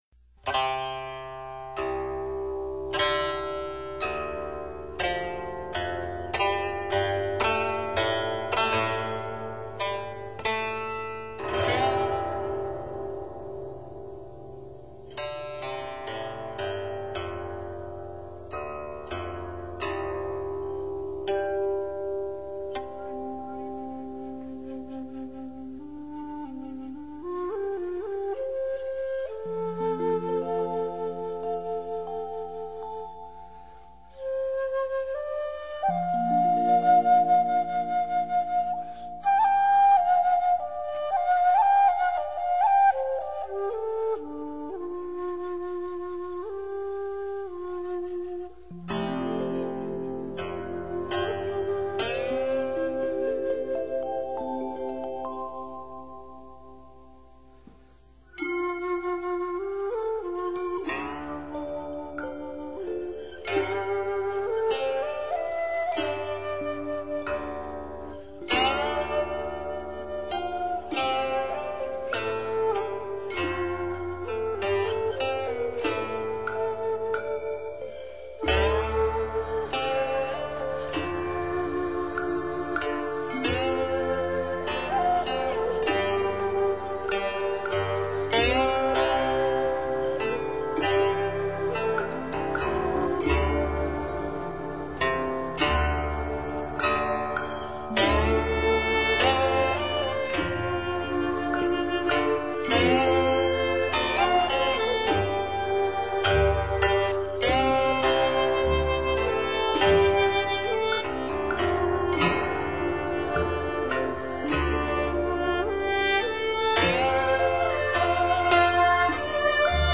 ☆高水准的演出，绝对让您感受名山圣水中缭绕不去的禅意。